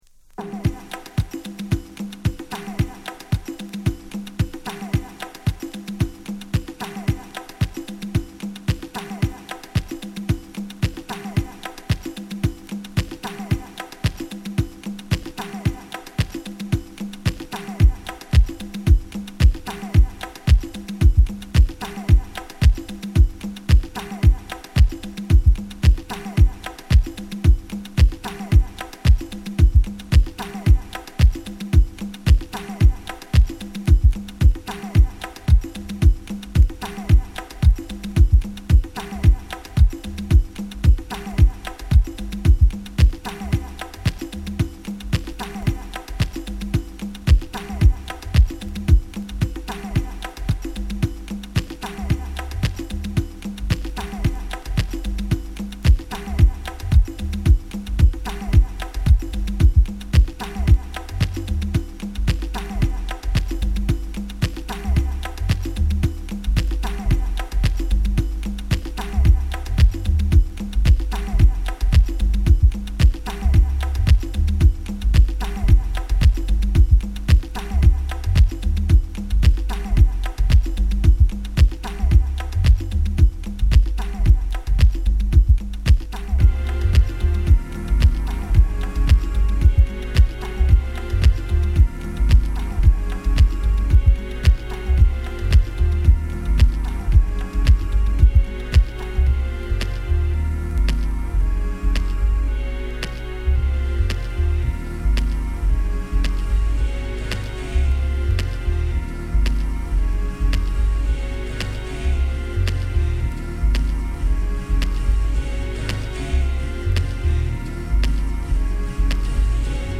Beatdown , Deep House , Detroit , House